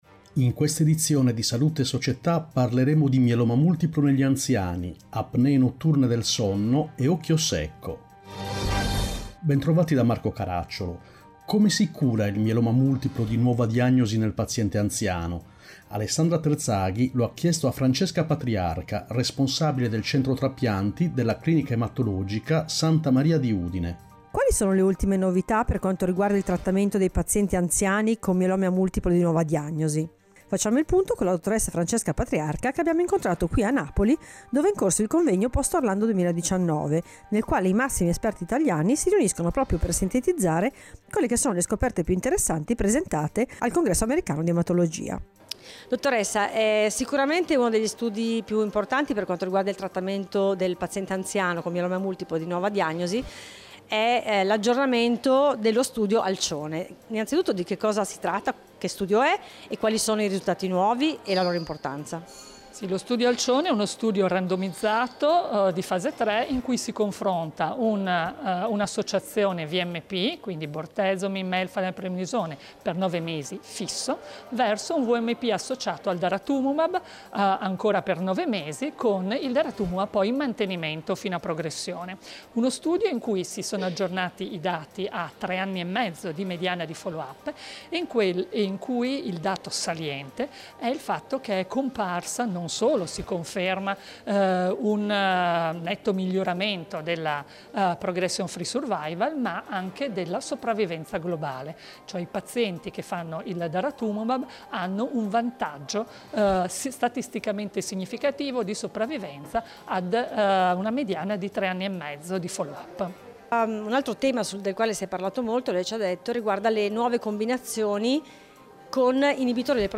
In questa edizione: 1. Anziani con mieloma multiplo 2. Apnee notturne del sonno 3. Occhio secco, come si cura Interviste